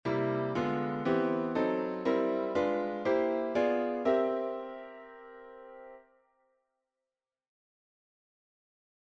Improvisation Piano Jazz
Accord de dominante V7